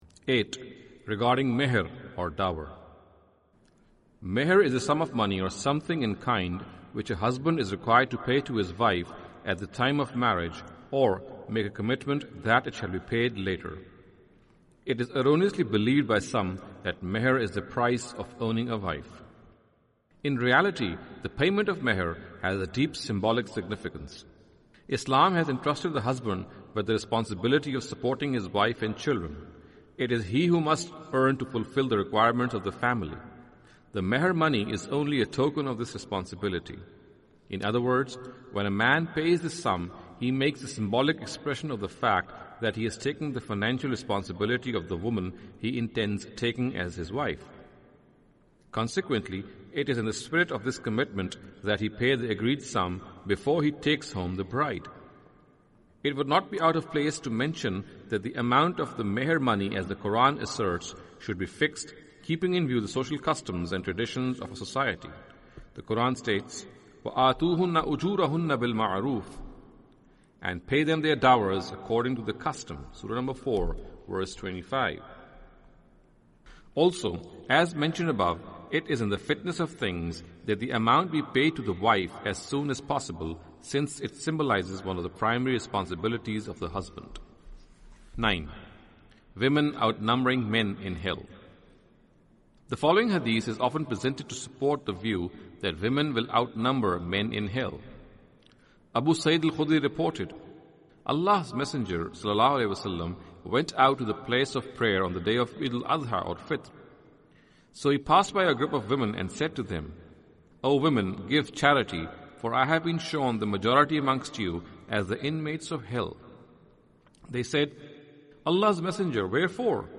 Audio book of English translation of Javed Ahmad Ghamidi's book "Islam and Women".